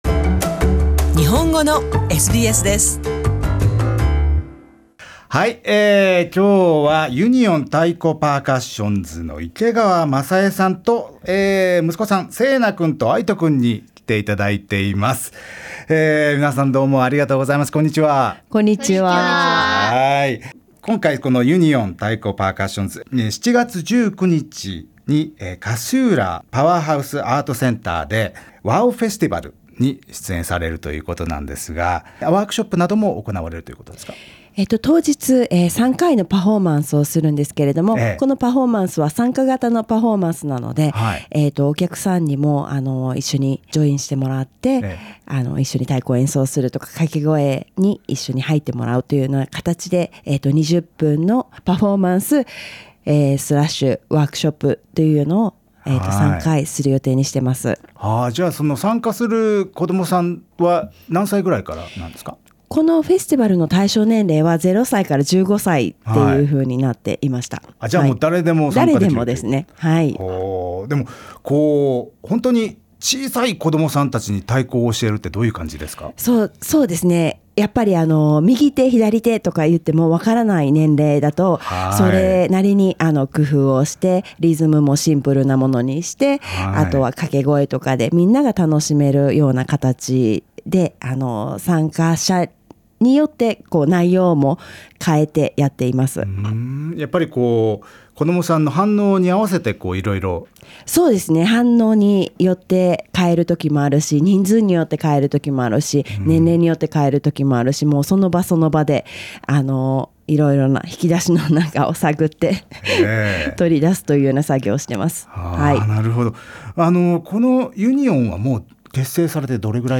家族のビートを届けたい 和太鼓グループ・ユニオン